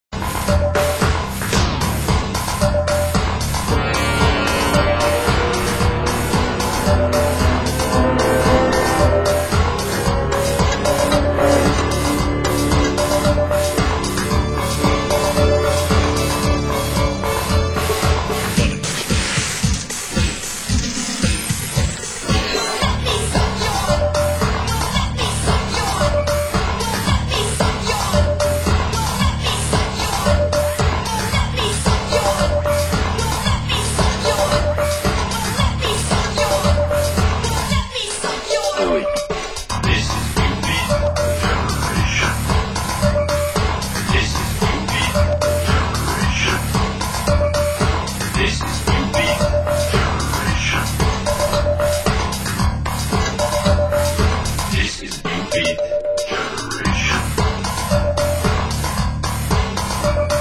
Genre: New Beat